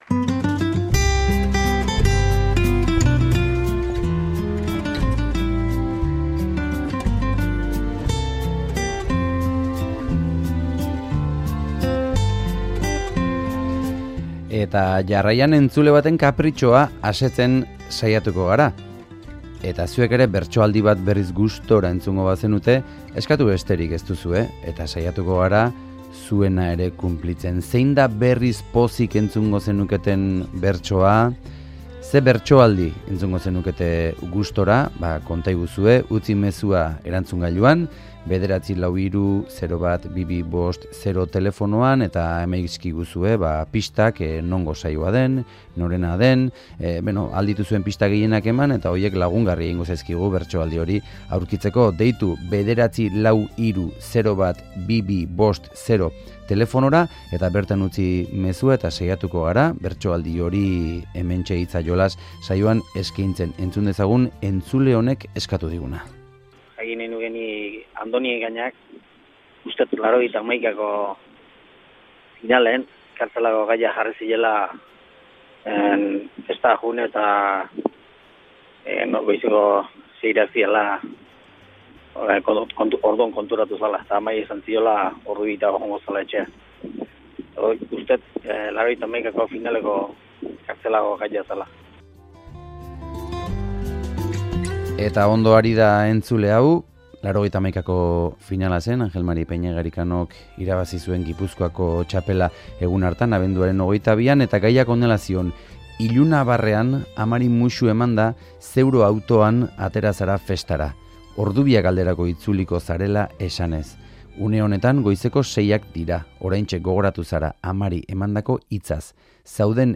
Donostiako Belodromoan 1991 Gipuzkoako Bertso Finala Andoni Egaña bakarka